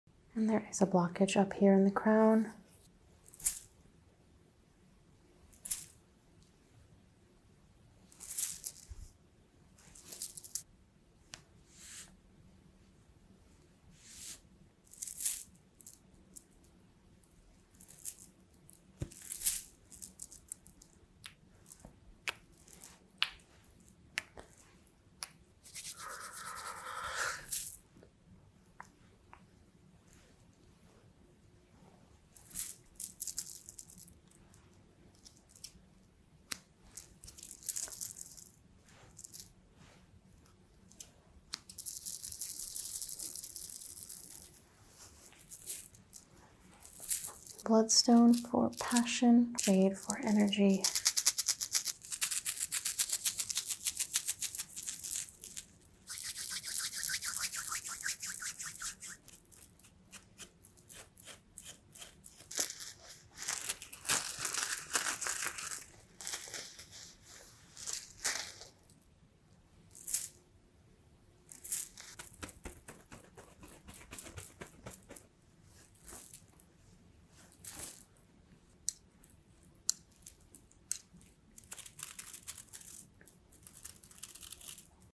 Which is your favorite ASMR sound effects free download
Which is your favorite ASMR reiki sound?